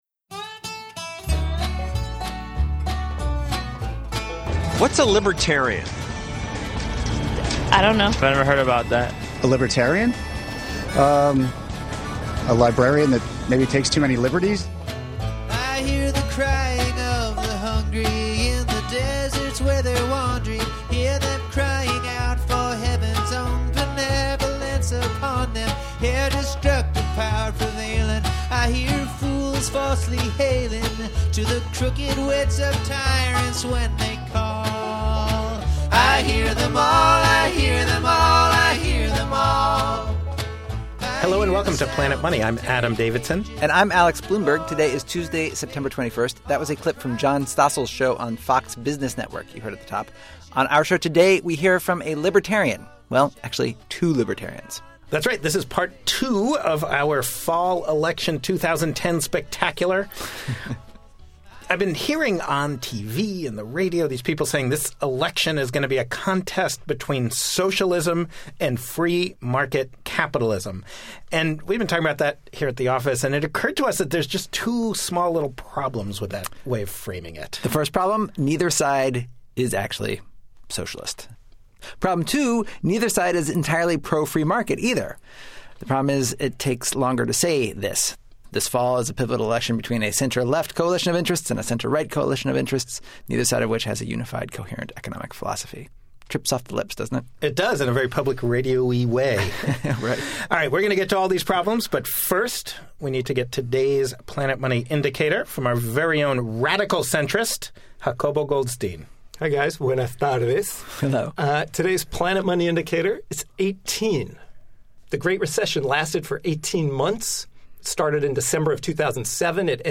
Today, two libertarians explain what libertarianism is and what they like and don't like about the Democrats and Republicans.